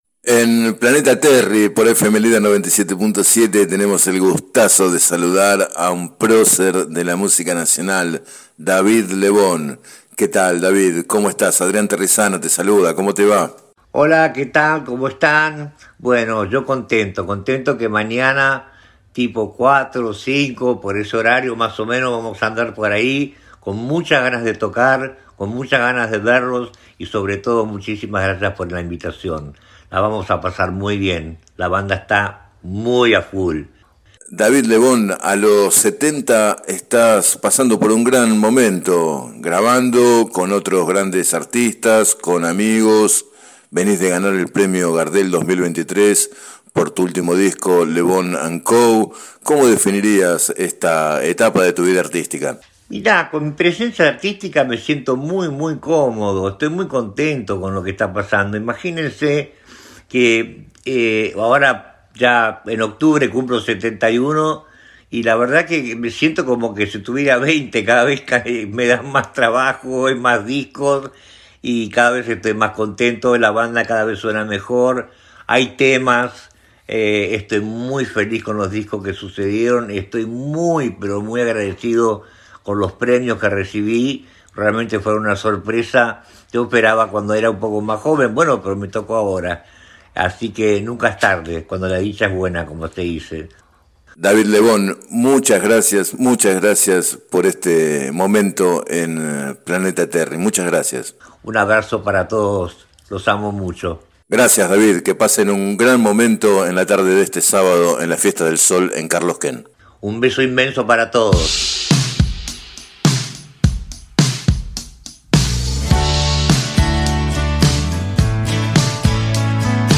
En declaraciones al programa Planeta Terri de FM Líder 97.7, Lebon se refirió a su expectativa y la de sus músicos por la presentación y también a su satisfacción por los premios recibidos recientemente que dan cuenta de que, a los 70 años, vive un gran momento artístico.